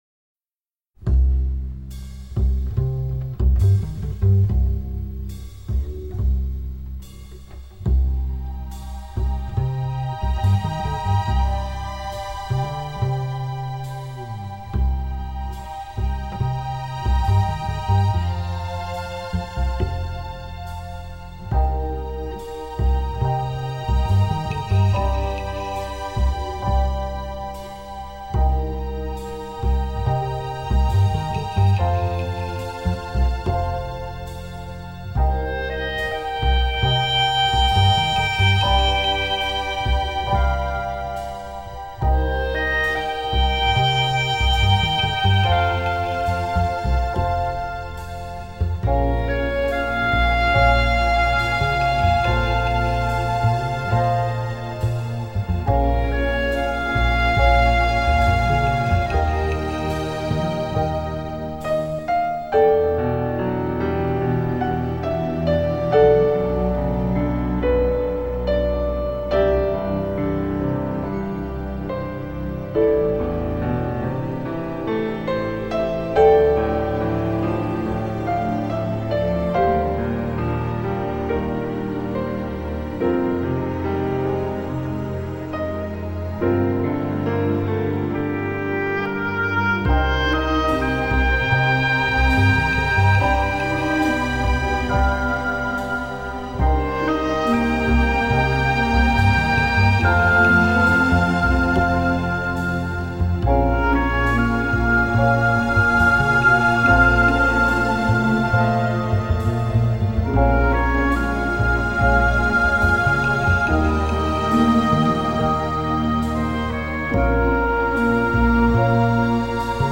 atmosphère de thriller néo-noir